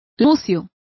Complete with pronunciation of the translation of pike.